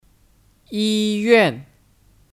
医院 Yīyuàn (Kata benda): Rumah sakit